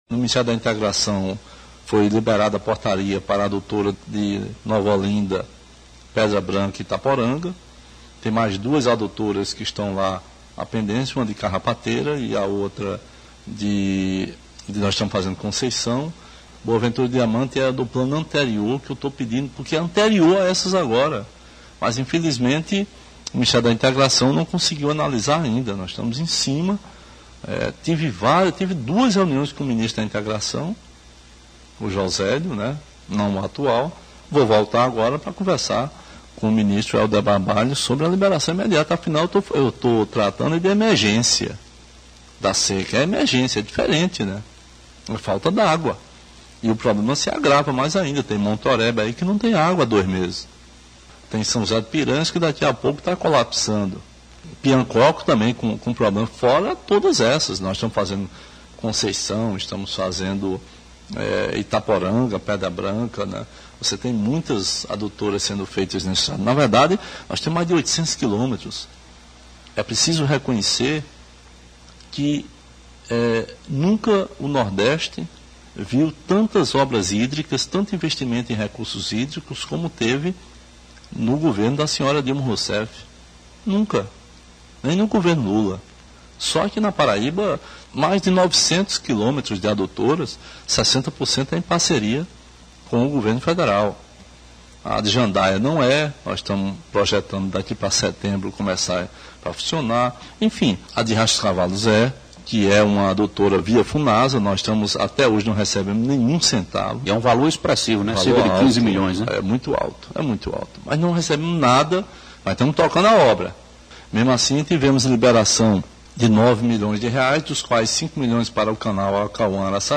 Ricardo em BonitoO governador, Ricardo Coutinho, falou esta semana no seu programa semanal de rádio sobre as adutoras que estão em andamento e outras que precisam ser iniciadas, urgentes, nas cidades afetadas pela crise hídrica nas microrregiões do Alto Piranhas e Vale do Piancó.
Ouça o governador abaixo: